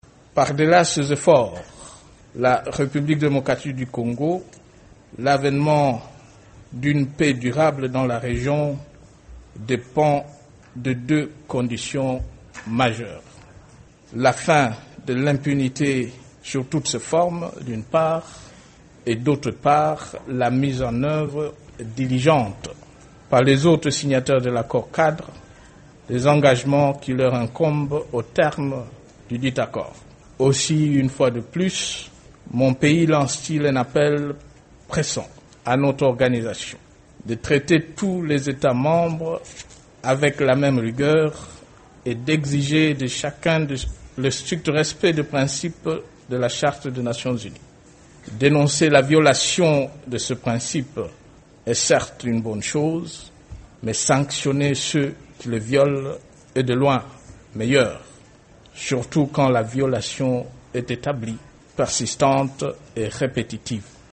La deuxième journée de la 68e session de l'Assemblée générale aura permis d'exposer les diverses crises politico-militaires du continent.
Ecoutez le président Joseph Kabila